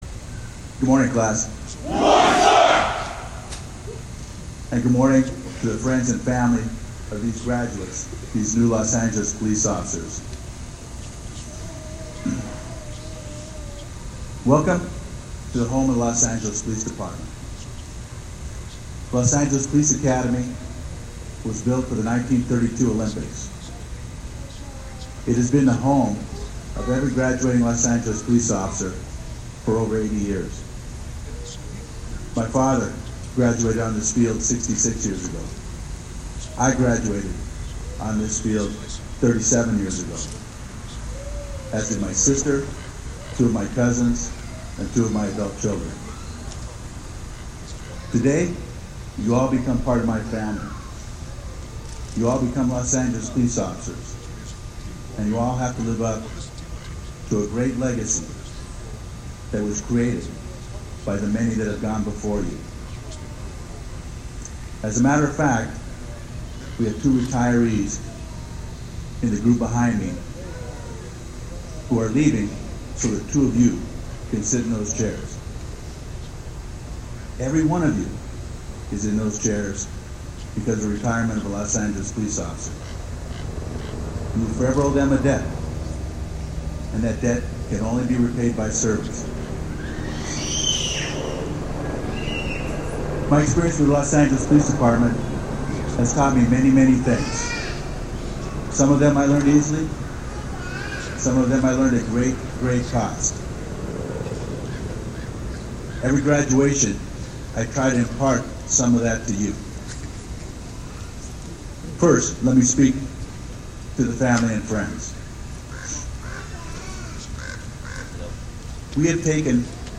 August 9, 2024 – On the athletic field at the Los Angeles Police Academy in Elysian Park, the newest members of the Department proudly stood at attention as they awarded their diploma of graduation.